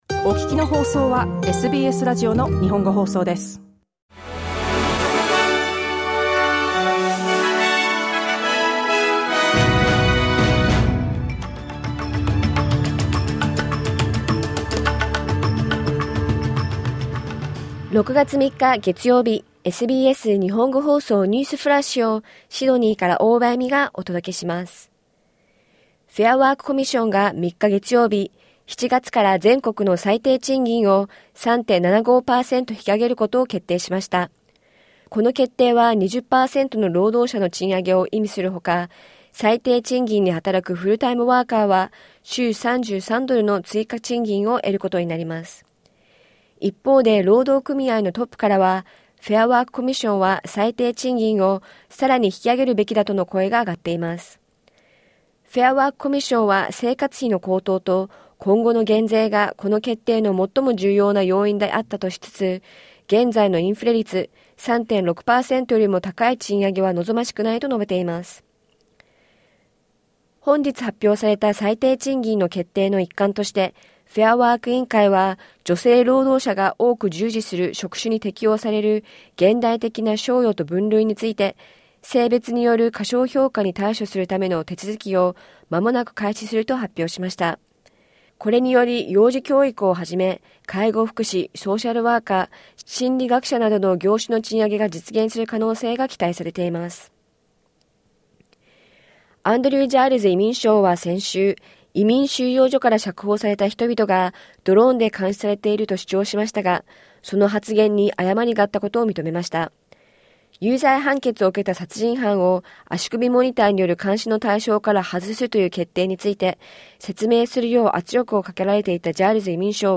SBS日本語放送ニュースフラッシュ 6月3日月曜日